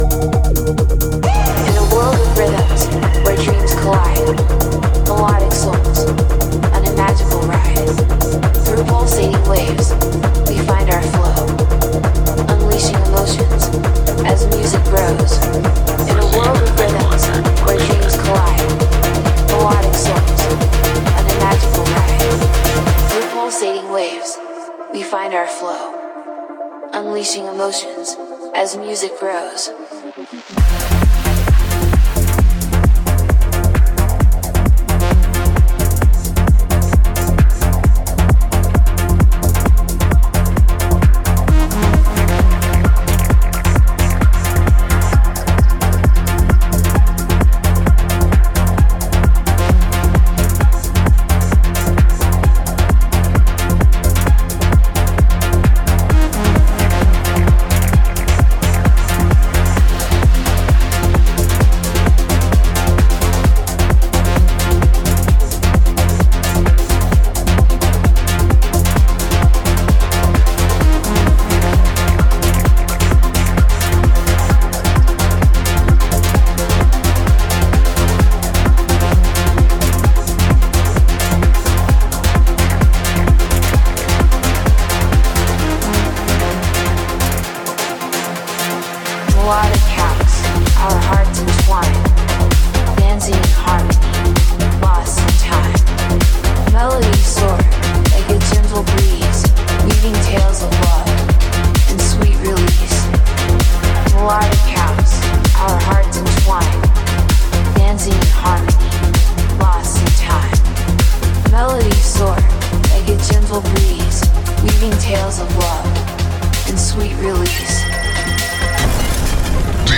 Genre: House, Electronic, Dance.